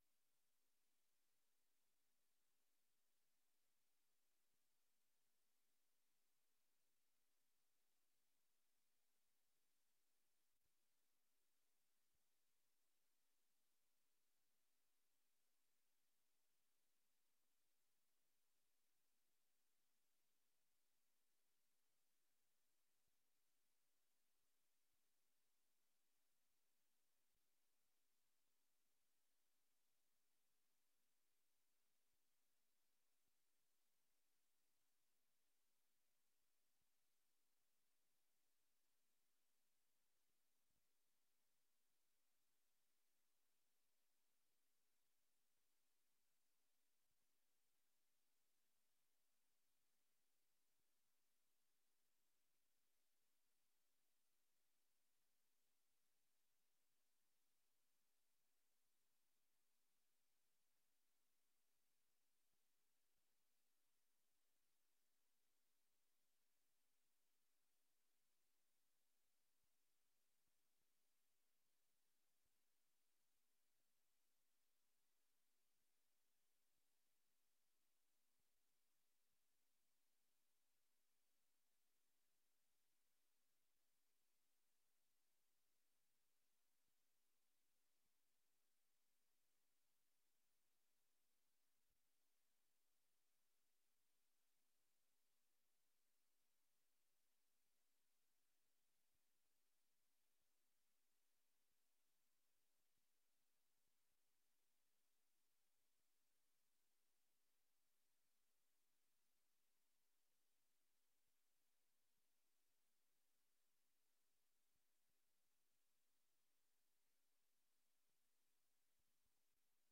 Beeld- en oordeelsvormende vergadering 27 juni 2024 19:30:00, Gemeente Dronten